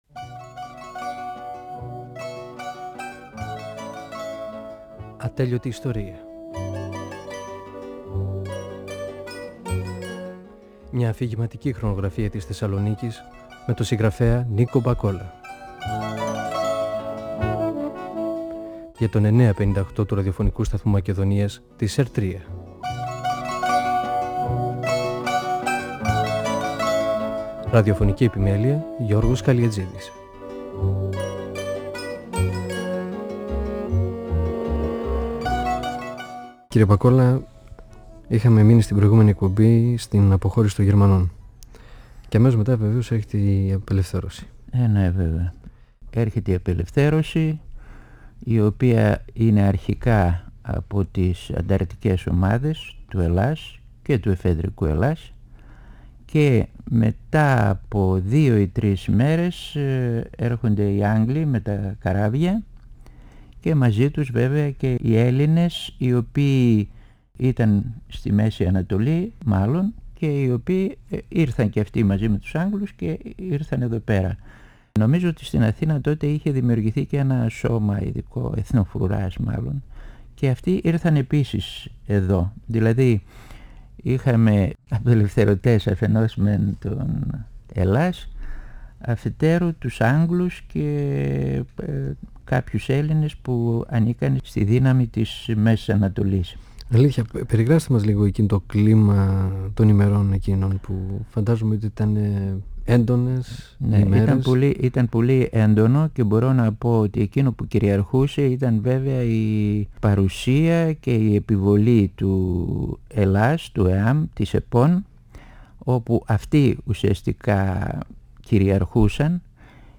Ο πεζογράφος Νίκος Μπακόλας (1927-1999) μιλά για τα παιδικά του χρόνια στη γειτονιά του, την περιοχή της οδού 25ης Μαρτίου. Μιλά για την απελευθέρωση της Θεσσαλονίκης.
Η συνομιλία-συνέντευξη